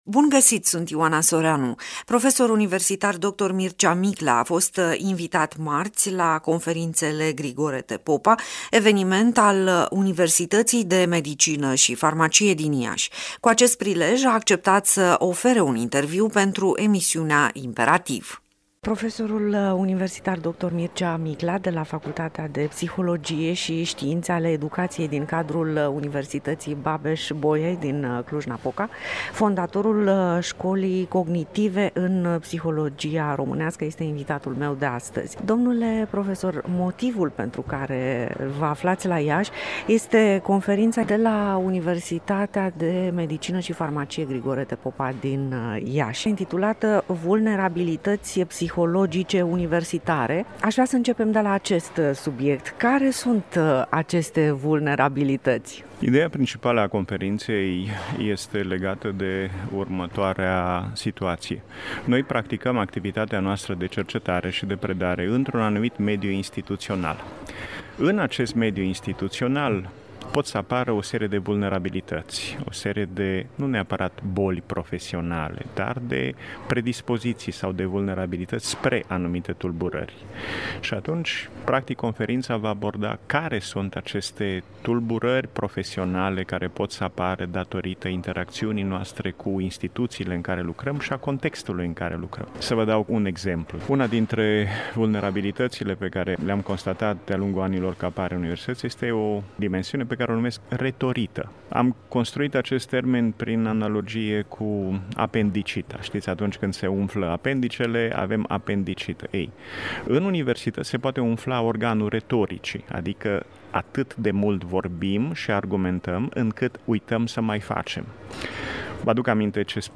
Cu acest prilej, a acceptat să ofere un interviu pentru emisiunea IMPERATIV.